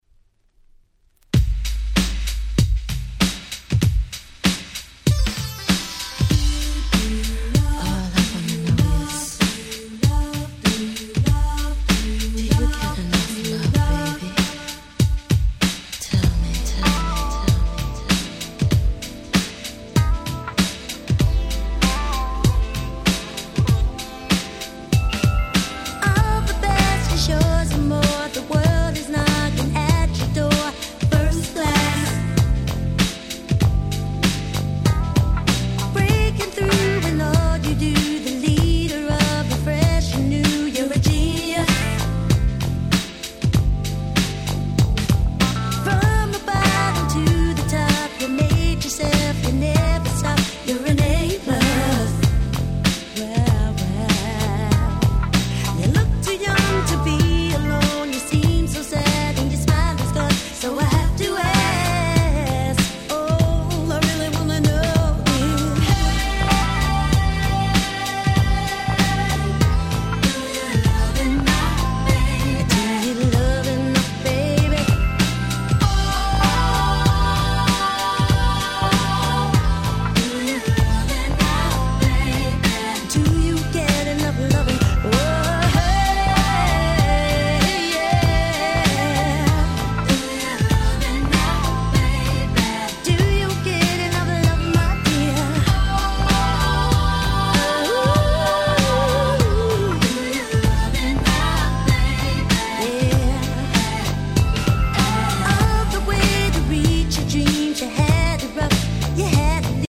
最強のUK Soul。
しっかりとしたBeatにムーディーな歌声がバッチリはまります。